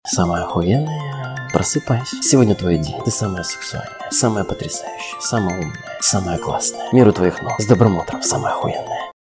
голосовые
матерные